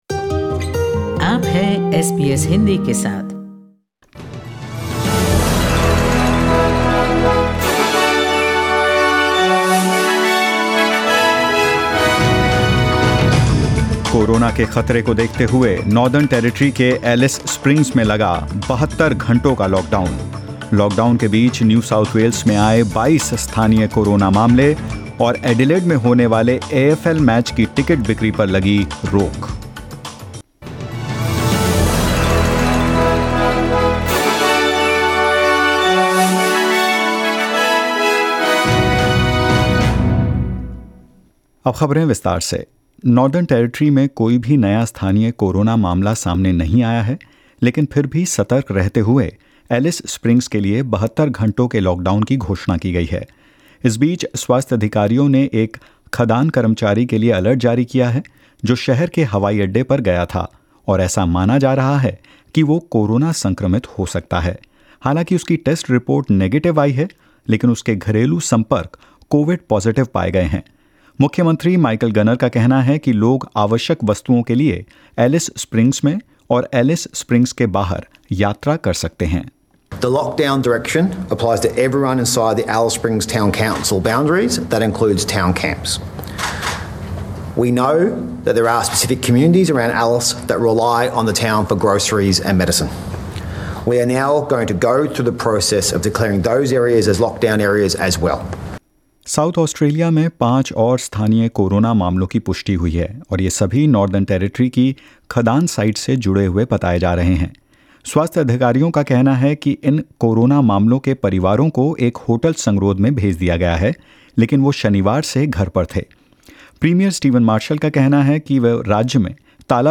In this latest SBS Hindi News bulletin of Australia and India: Twenty-two new community cases of coronavirus identified in New South Wales; ticket sales for an AFL match to be held in Adelaide this weekend temporarily suspended and more.